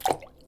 drop_in_lava.ogg